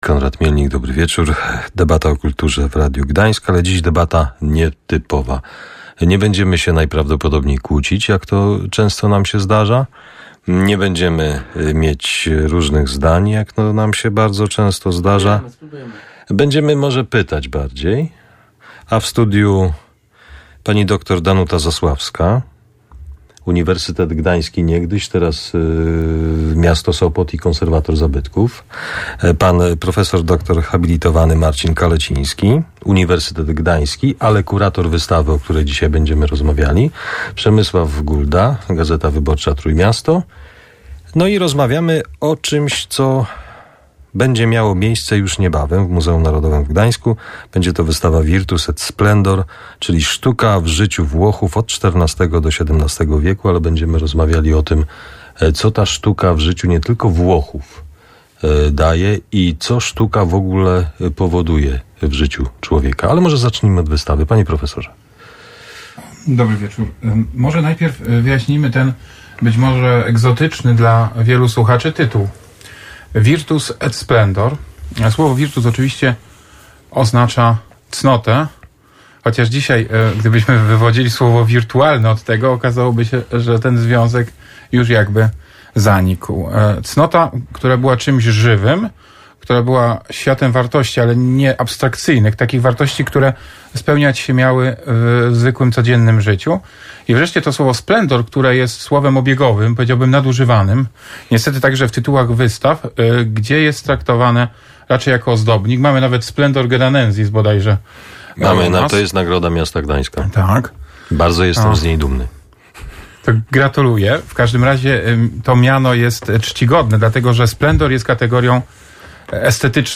debata2312.mp3